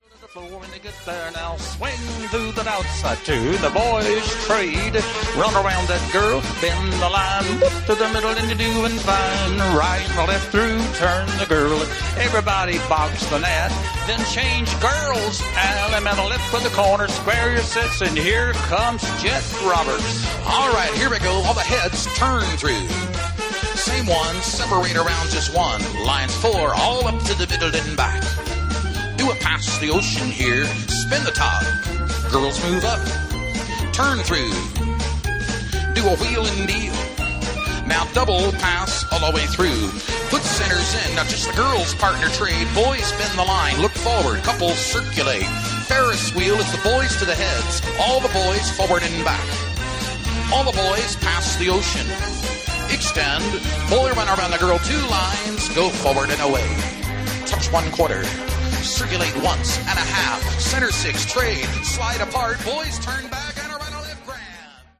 Category: Patter
Patriotic